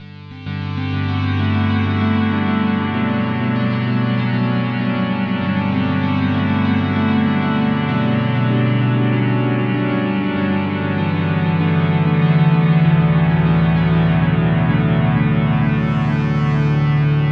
supplemental file -echo